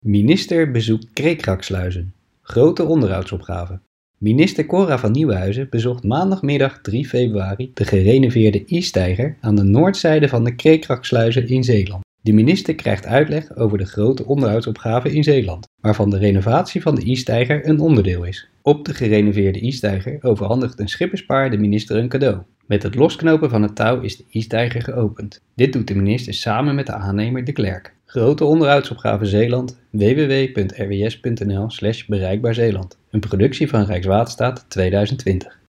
Minister Cora van Nieuwenhuizen opende op 3 februari 2020 de gerenoveerde E-steiger aan de noordzijde van de Kreekraksluizen.